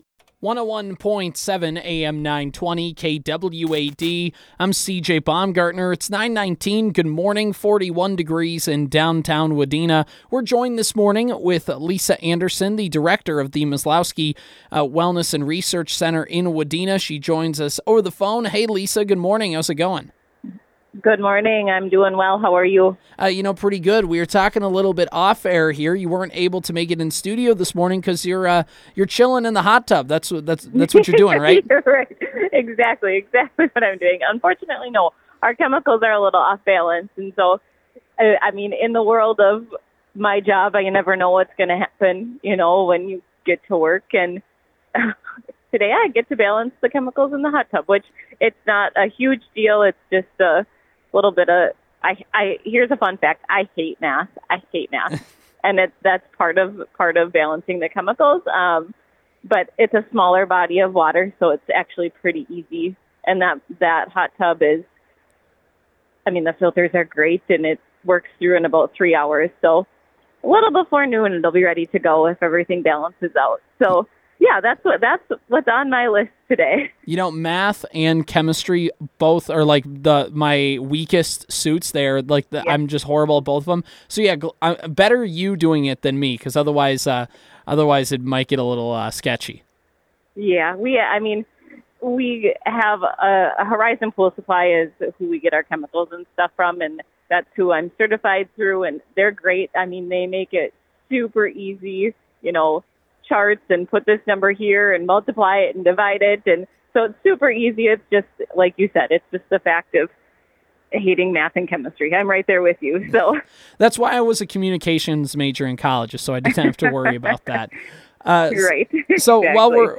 Maslowski Wellness And Research Center Interview: 10/17/23 – Superstation K-106